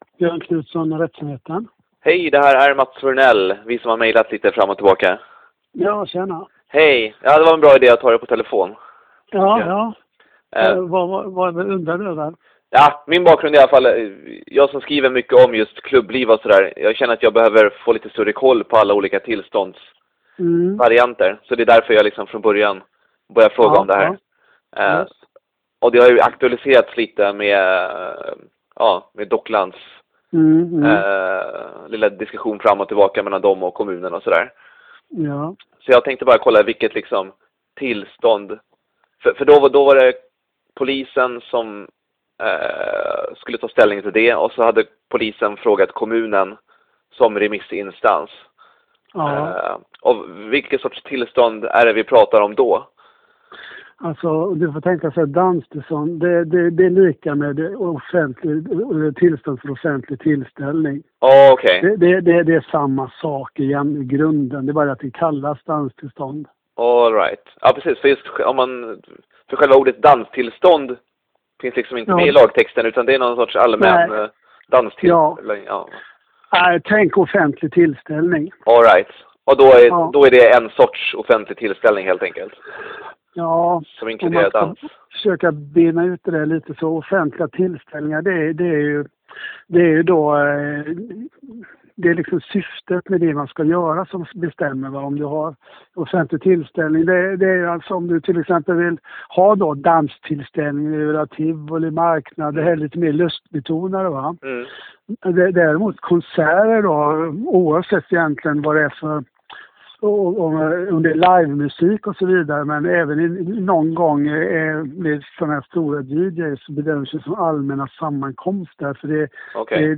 I detta tio minuter långa samtal går vi inte in på alkohollagen, utan fokuserar på det som i folkmun kallas för danstillstånd.